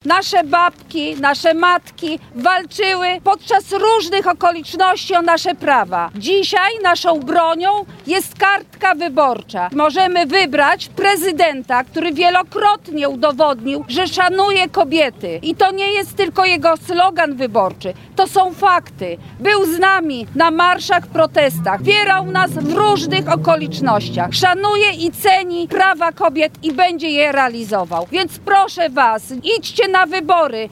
W Lublinie, tak jak w innych miastach Polski, w sobotę (08.03) została zainaugurowana akcja Kobiety z Trzaskiem – Razem Dla Przyszłości. Na placu Litewskim kobiety do głosowania na kandydata na prezydenta Polski Rafała Trzaskowskiego zachęcała między innymi europosłanka Marta Wcisło.